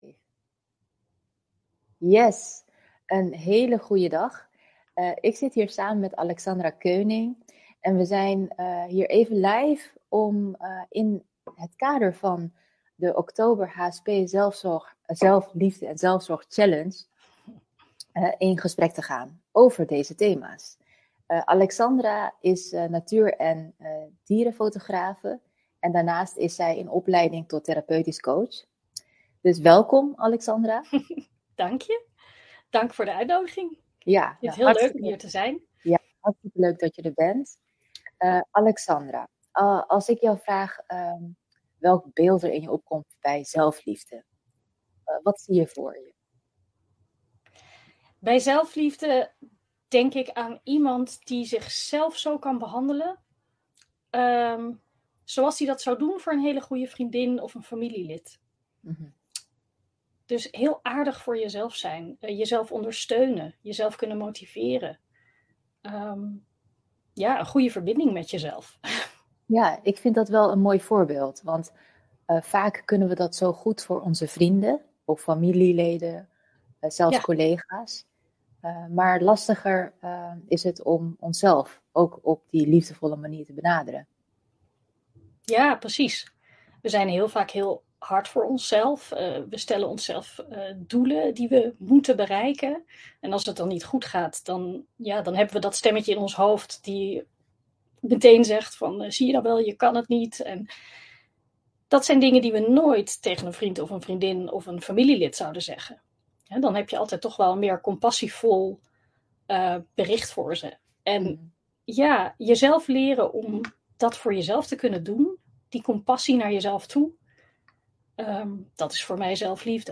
Zelfliefde en zelfzorg: Een gesprek over compassie en persoonlijke groei